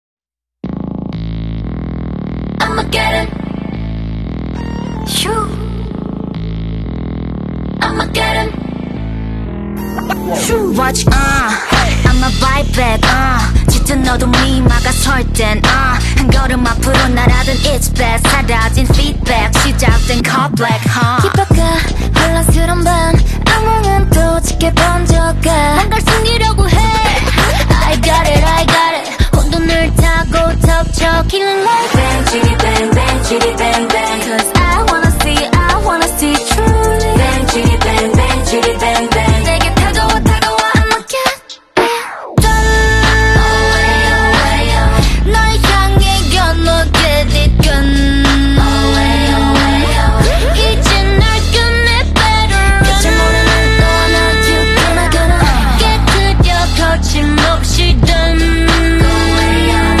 (Tried to fix the bad quality😅)